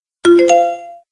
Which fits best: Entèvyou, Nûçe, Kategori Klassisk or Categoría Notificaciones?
Categoría Notificaciones